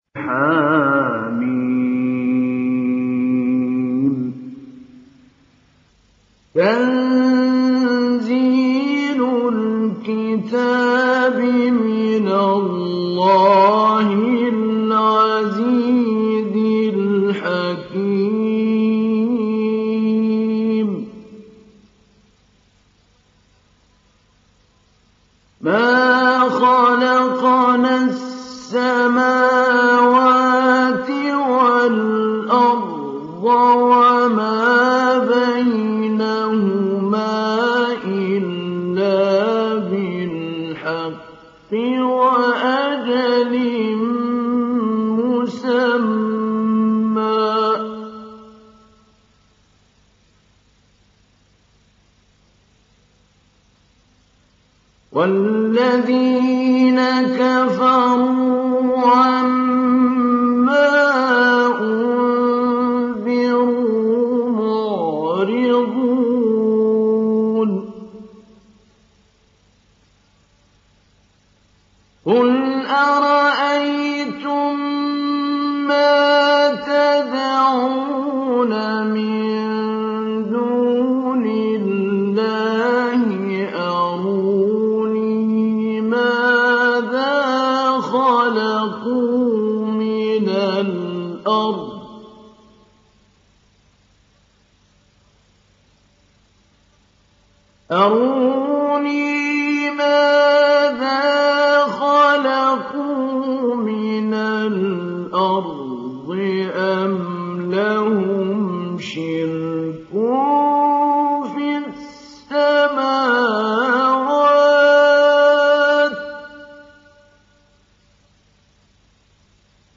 دانلود سوره الأحقاف محمود علي البنا مجود